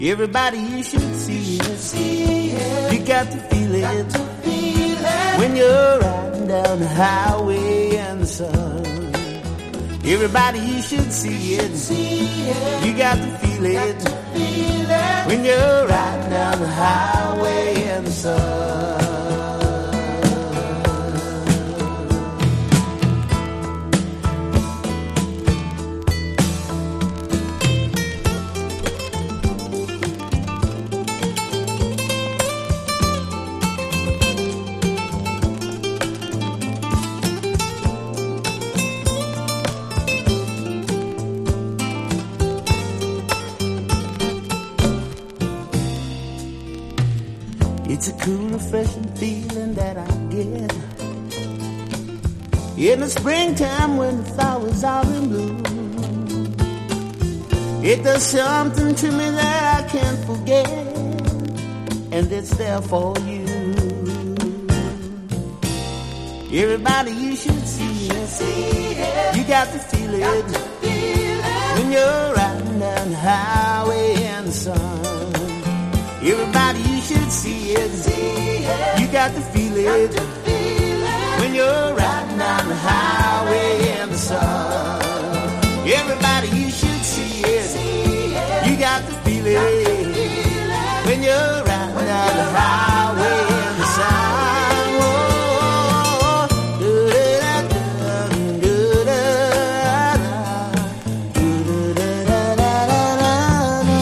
ブリージンなハワイアンA.O.R./メロウ・ソウル